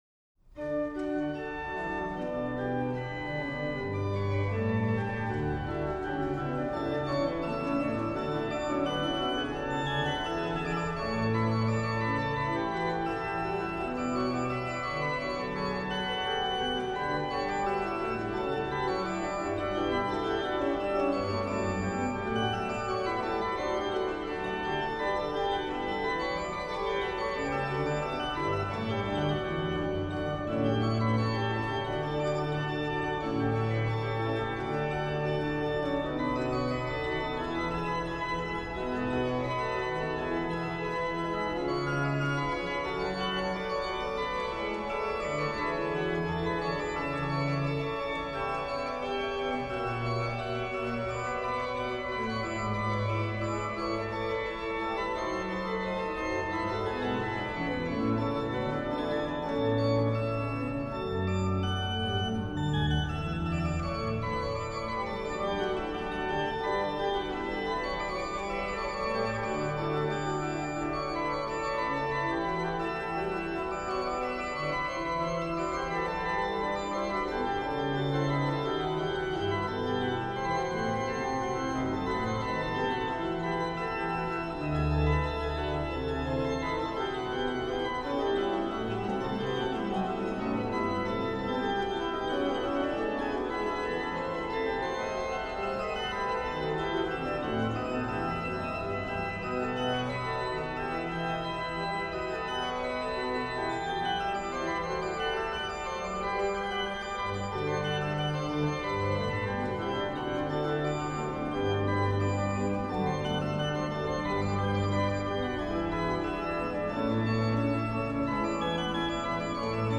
rh: BW: Ged8, Rfl4, Oct2
Ped: Oct8, Oct4